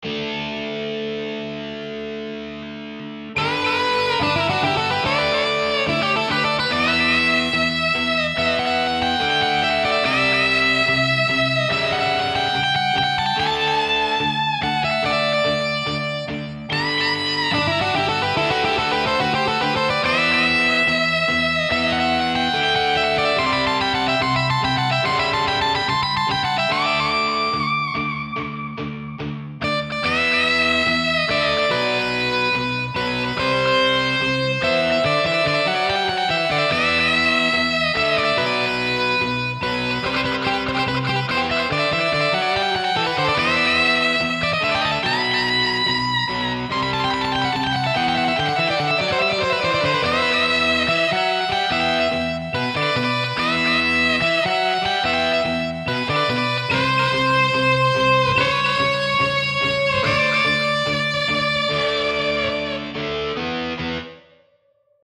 もう一曲、こちらはギターパートだけで、以前に作った、Tearsという曲のギターソロに、少しだけ手を加えたものです。
さらに、同じ曲で、ギターアンプのVSTを、簡単に使えそうな物に刺し替えてみました。ギターアンプのVSTを差し替えただけで、その他のVSTは、変更も調節もまったくしていません。
今回はサウンドフォントを使っていますが、自分で弾いたギターの音でも、こんなふうに跡からギターアンプを変更して、音色を変えることが出来ます。
使用したギターアンプは、左が、Juicy77で、右が、Blue Cat AudioのBC Free Amp、そしてセンターは、SimulAnalog Guitar SuiteのJCM900です。